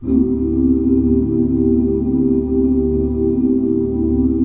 DSUS13 OOO.wav